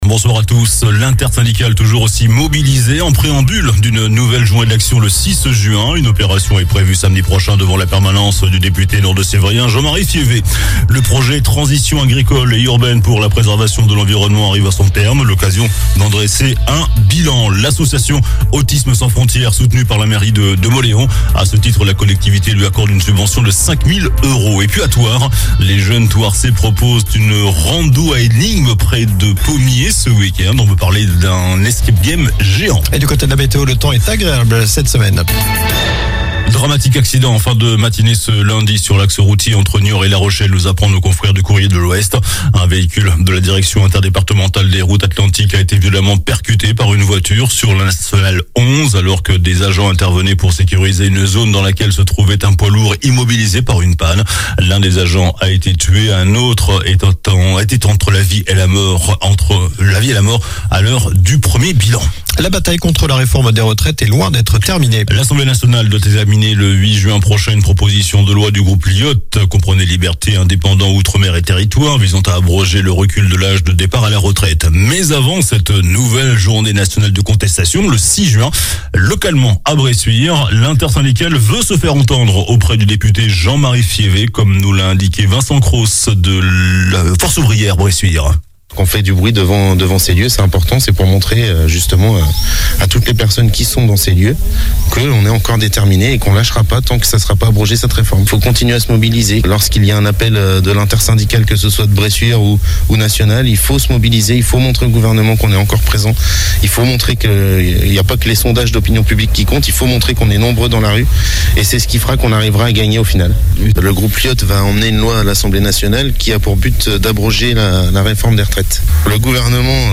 Journal du lundi 22 mai (soir)